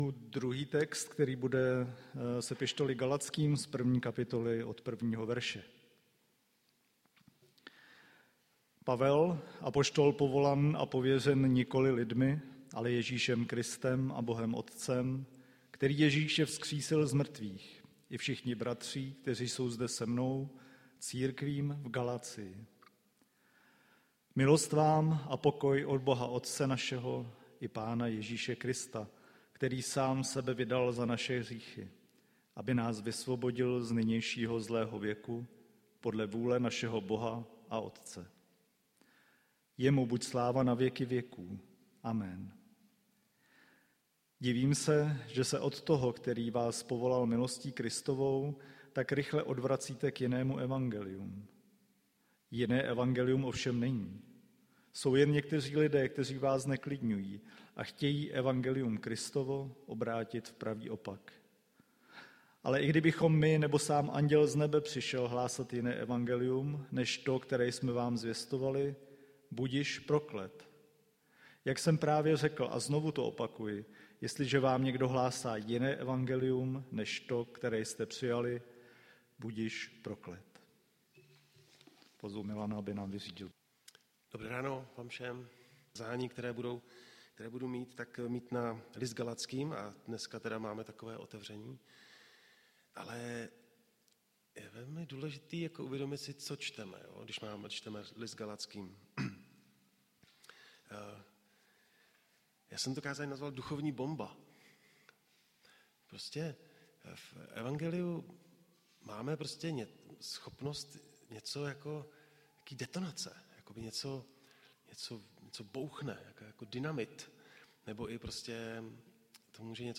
Kázání 2025 Listopad Kázání 2025-10-26